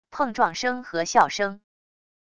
碰撞声和笑声wav音频